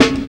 108 FEEL SNR.wav